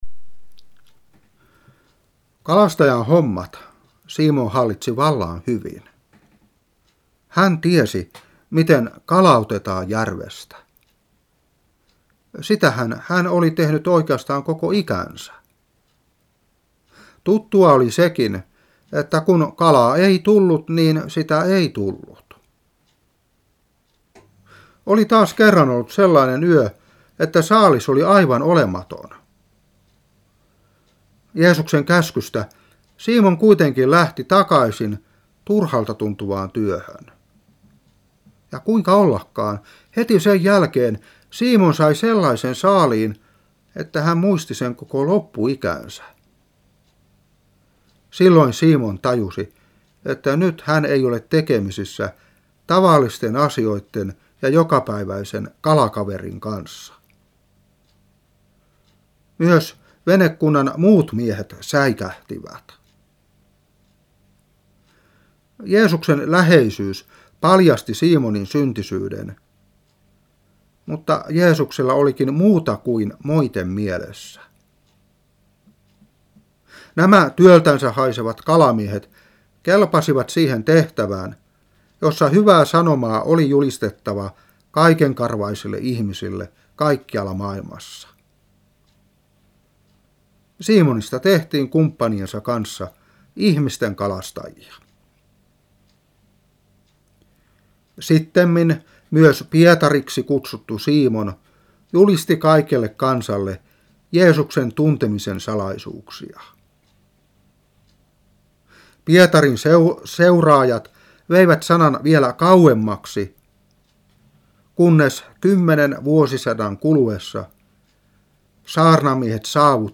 Saarna 2003-5.